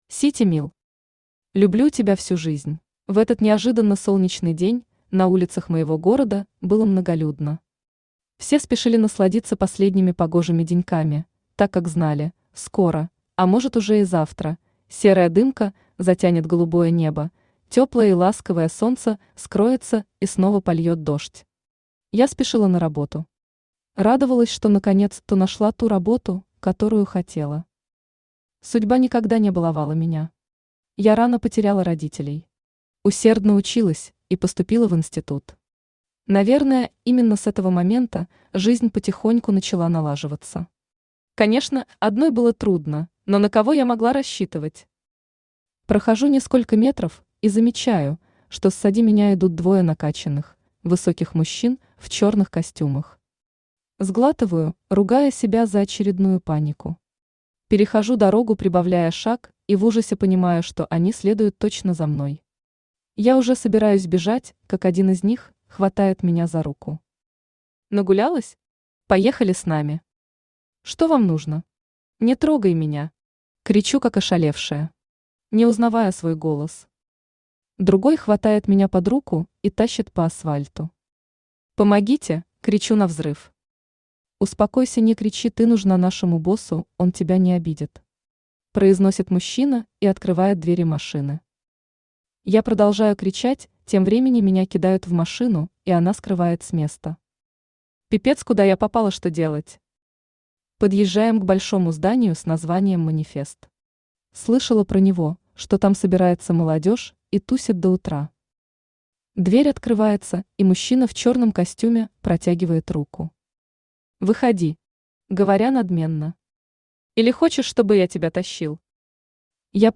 Aудиокнига Люблю тебя всю жизнь Автор Сити Мил Читает аудиокнигу Авточтец ЛитРес.